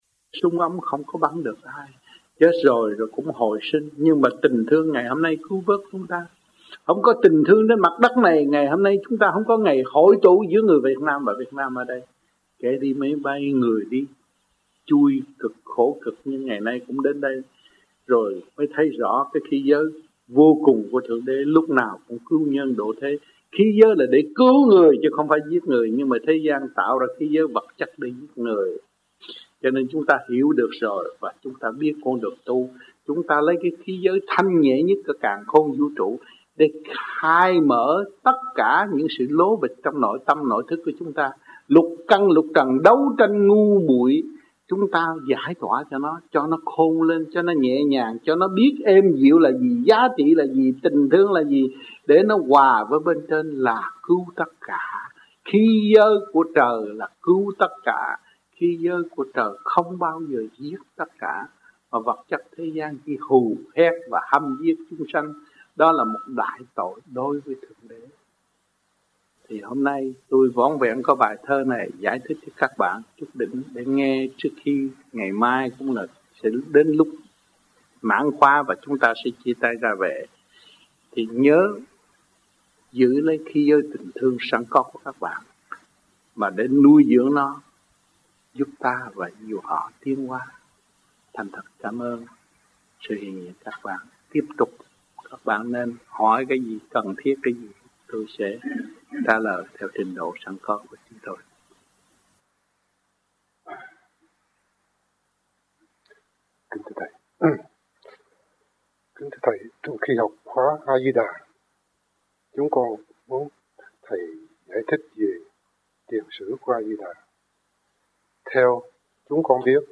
1986 Đàm Đạo
1986-09-14 - VẤN ĐẠO - KHOÁ 1 - THIỀN VIỆN QUY THỨC